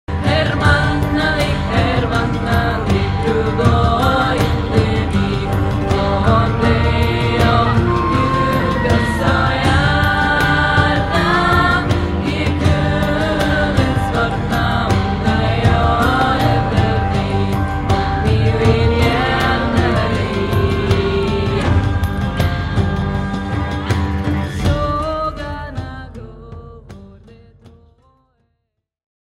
sweddish folk ballad